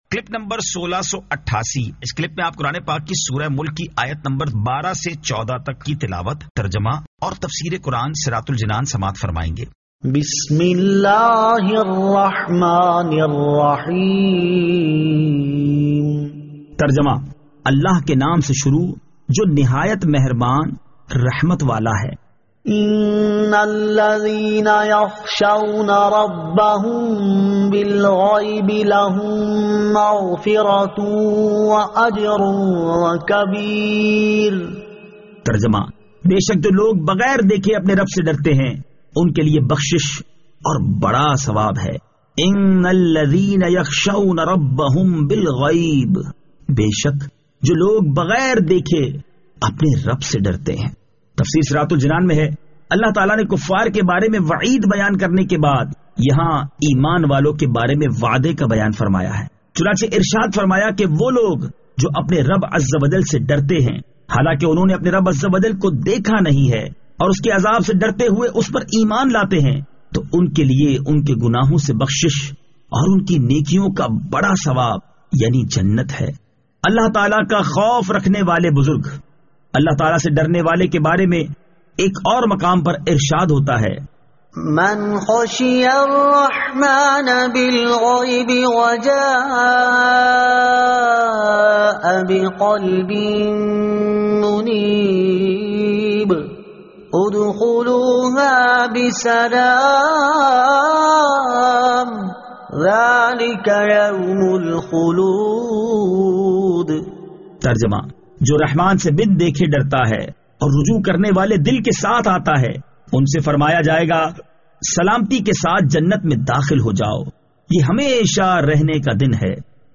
Surah Al-Mulk 12 To 14 Tilawat , Tarjama , Tafseer